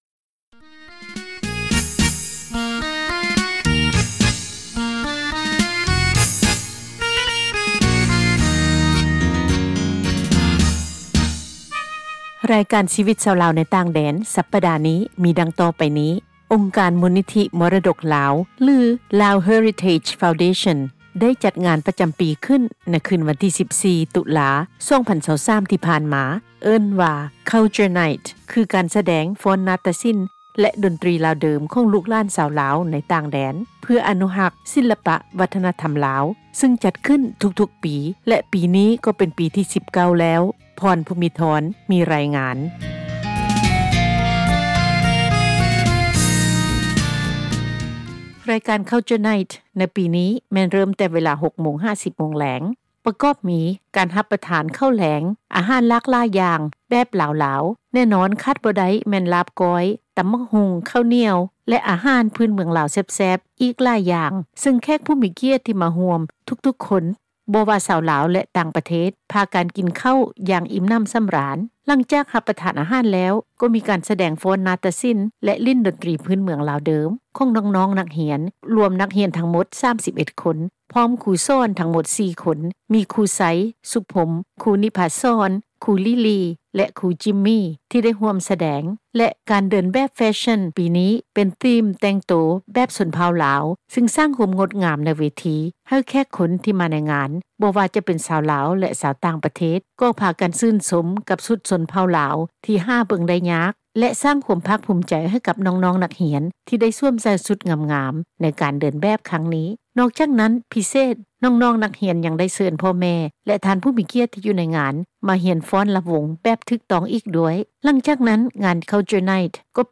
ສະແດງ ຟ້ອນນາຕສິລ, ດົນຕຣີລາວເດີມ ຂອງບັນດານັກຮຽນ ມູລນິທິ ມໍຣະດົກລາວ ໃນຄ່ຳຄືນ ວັນທີ 14 ຕຸລາ ທີ່ຣັຖ ເວີຈີເນັຽ, ສະຫະຣັຖ ອາເມຣິກາ.
ສັມພາດ ສະມາຊິກ ມູລນິທິ ມໍຣະດົກລາວ